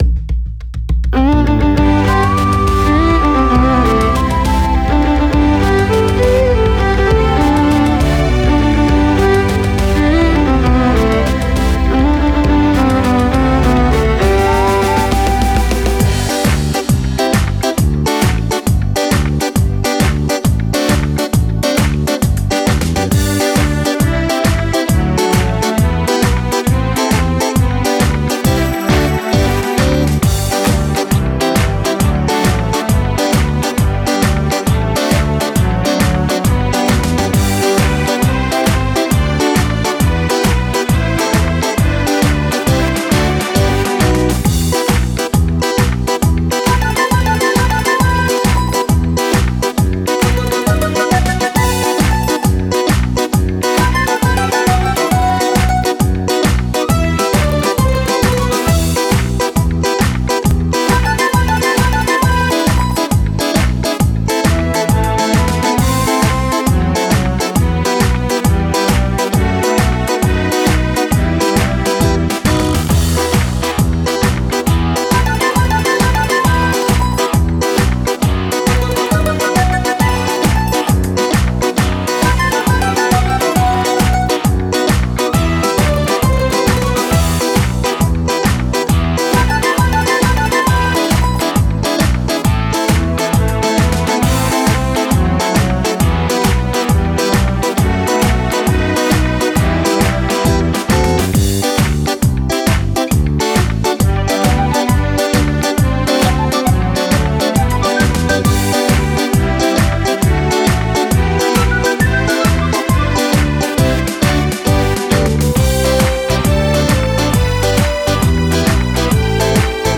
tarantella per organetto